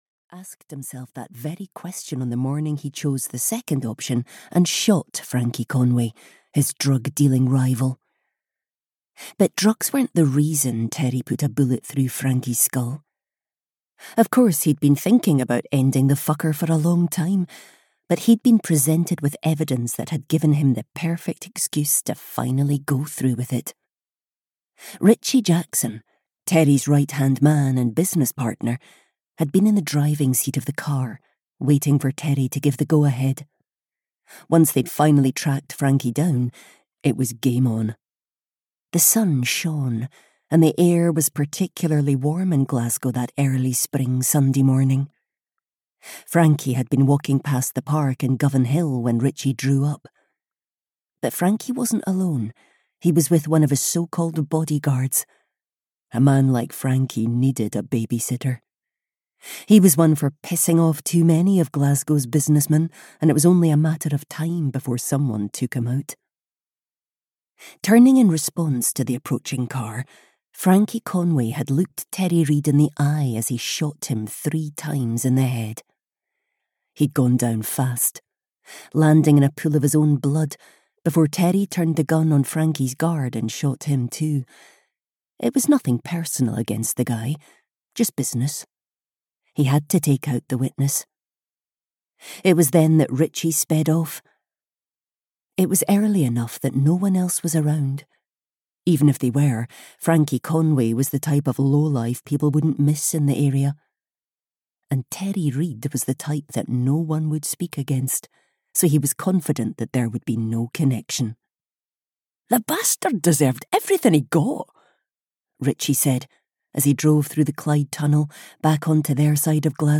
The Housewife (EN) audiokniha
Ukázka z knihy